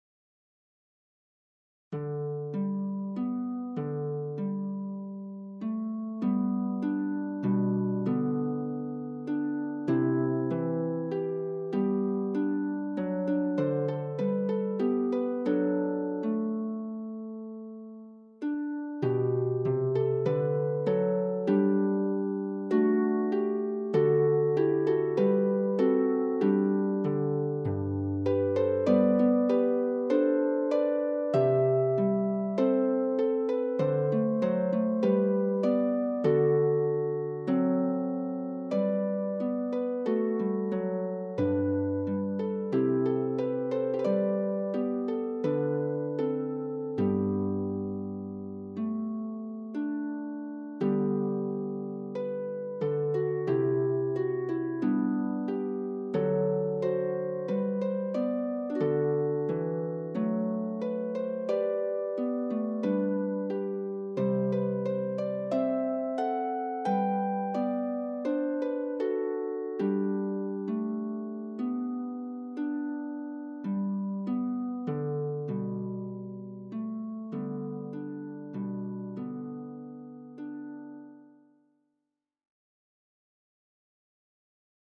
German carol